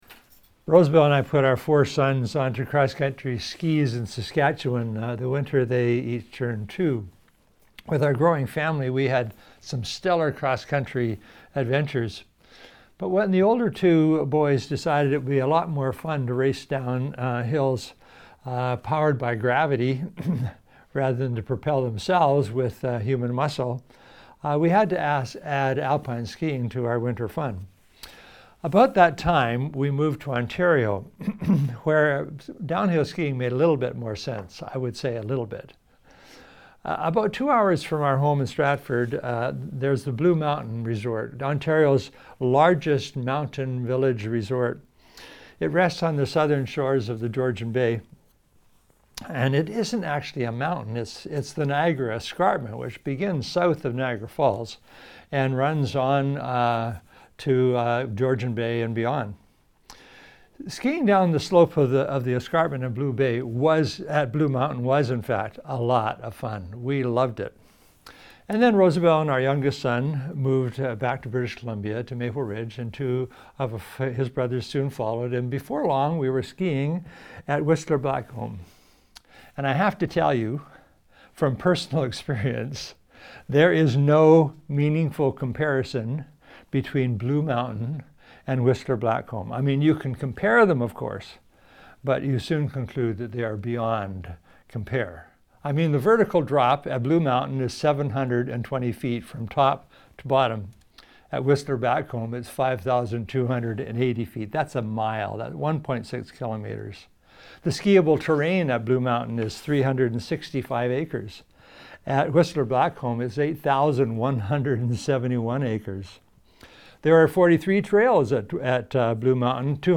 SERMON NOTES There is no comparison between active allegiance to Jesus and any other approach to life.